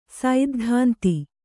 ♪ saiddhānti